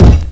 PSP/CTR: Also make weapon and zombie sounds 8bit
barricade.wav